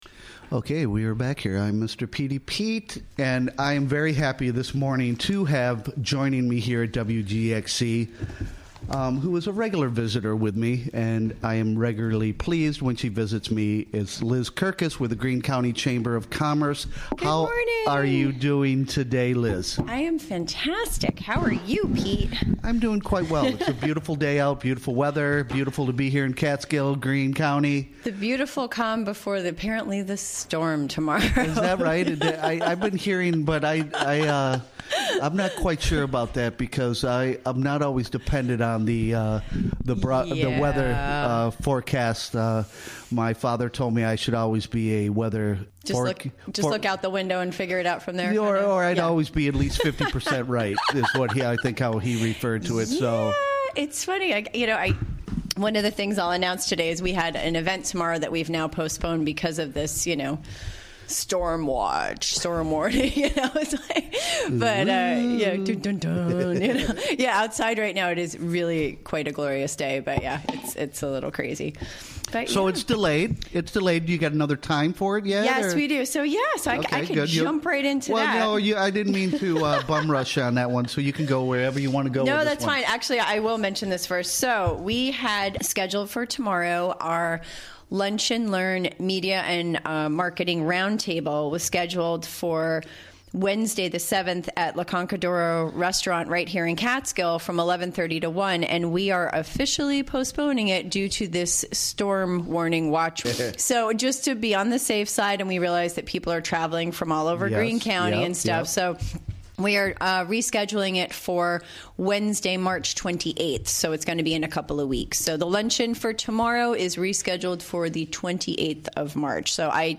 Recorded during the WGXC Morning Show of Tuesday, March 6, 2018.